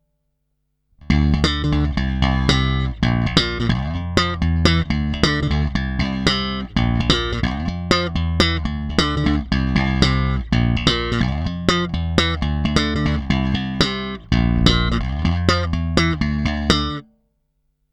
Není-li řečeno jinak, následující nahrávky jsou provedeny rovnou do zvukové karty a jen normalizovány, basy a výšky na nástroji nastavené skoro naplno.
Slap na oba snímače